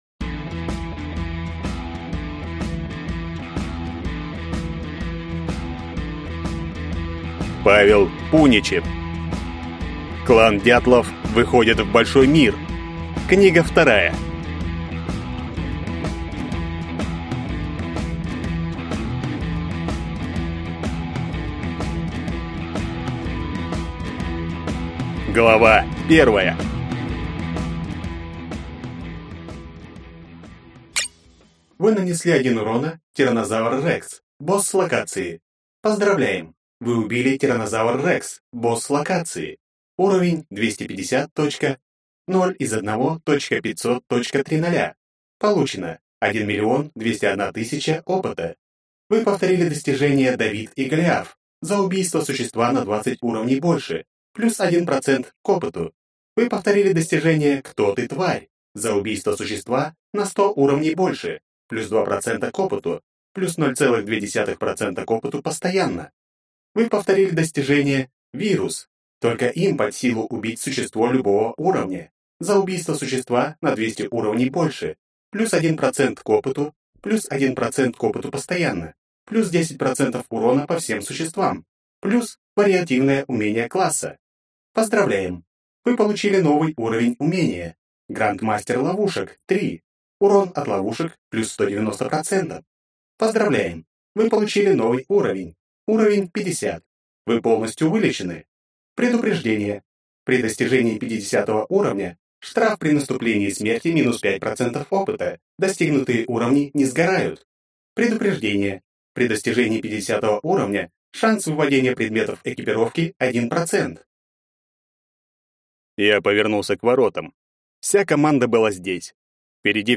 Аудиокнига Клан «Дятлов». Книга 2. Выходит в большой мир | Библиотека аудиокниг